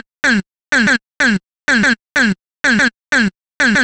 cch_vocal_loop_ease_125.wav